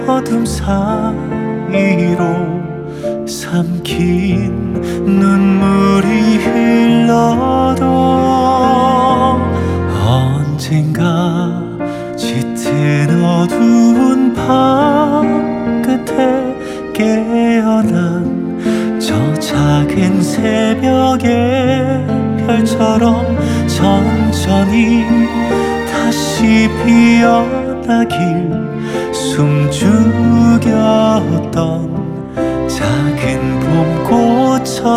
K-Pop Pop Soundtrack
Жанр: Поп музыка / Соундтрэки